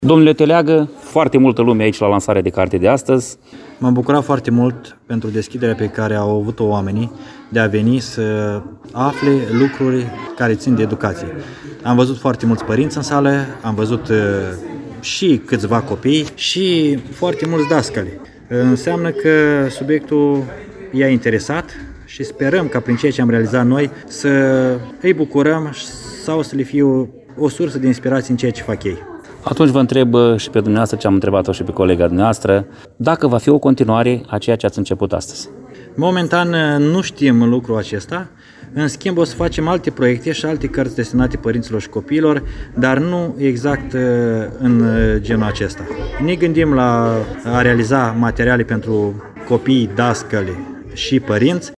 Pe 30 noiembrie, la Biblioteca Tudor Flondor din Rădăuți au avut loc o serie de lansări de carte.